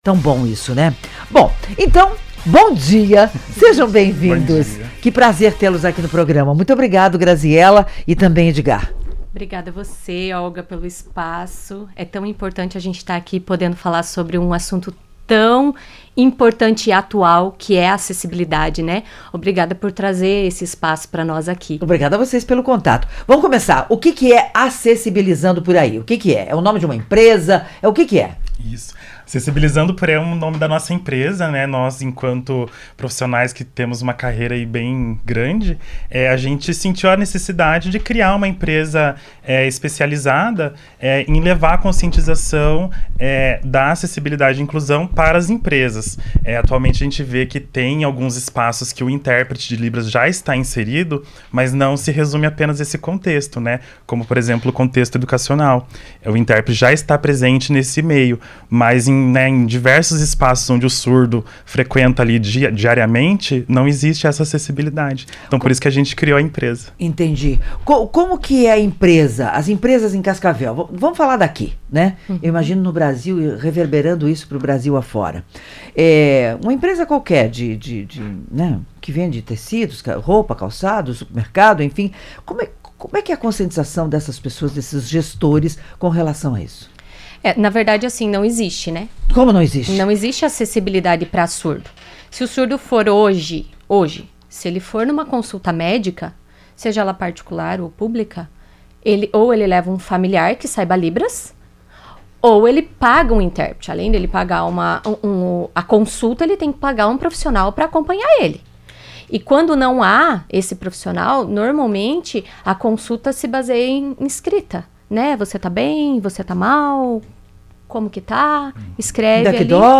Neste sábado (27), o Revista CBN debateu o tema crucial da acessibilidade para pessoas com deficiência auditiva.